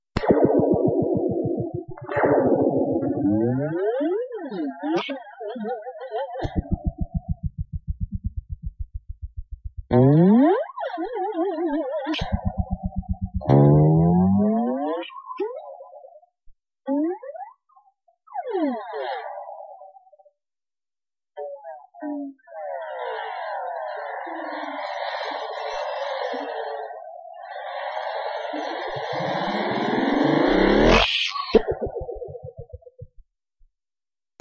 Descarga de Sonidos mp3 Gratis: laser dibujos animados.
alien_6.mp3